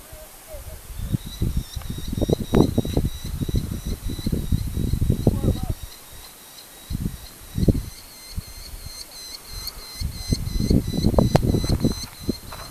Im Geröll am Fuße der Basaltsäulen nisten Vögel, deren Rufe von der Wand zurückgeworfen laut hallen.